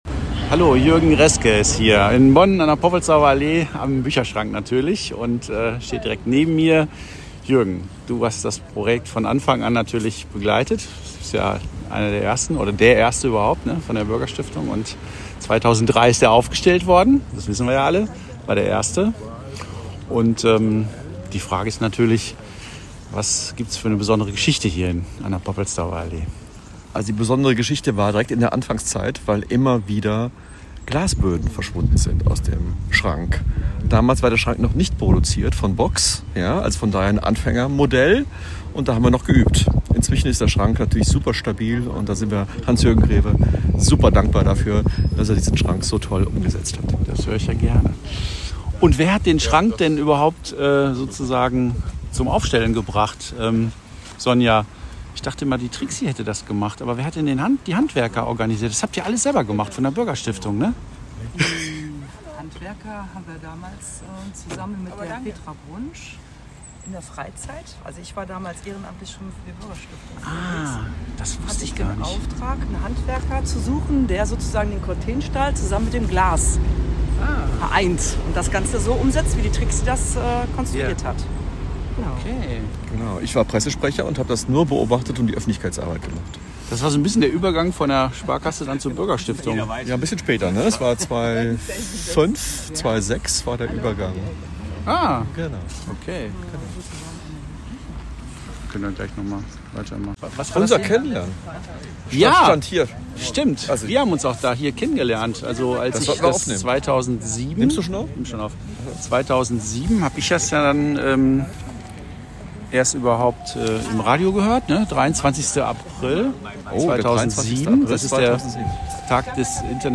007TourfuerKultur-BonnPoppelsdorferAllee.mp3